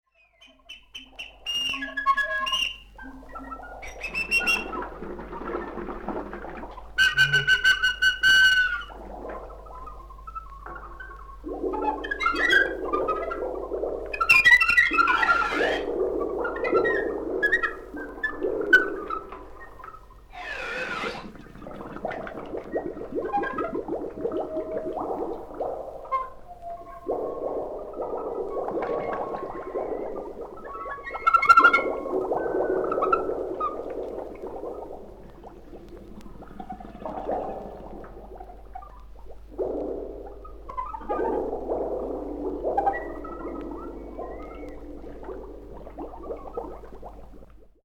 謎な騒音あれやこれや。
即興